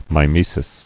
[mi·me·sis]